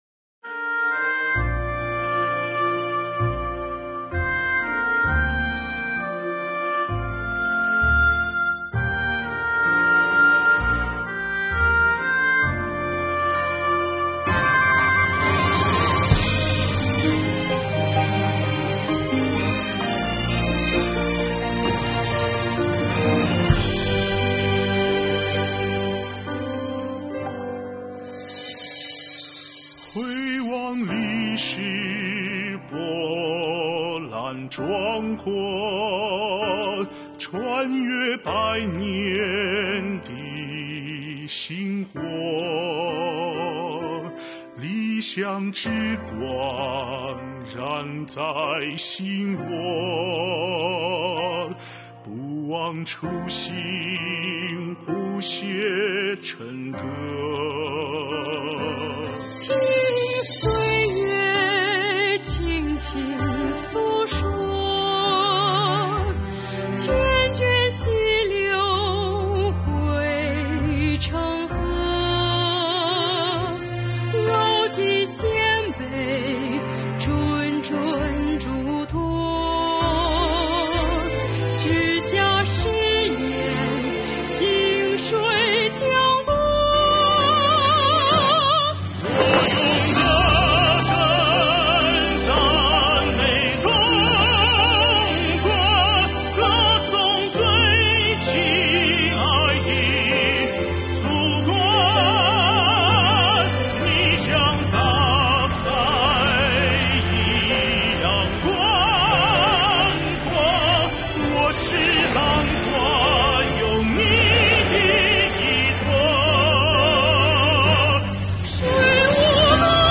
在中国共产党成立100周年之际，广西税务干部创作歌曲《税务蓝 颂中国》，用歌声对党深情告白。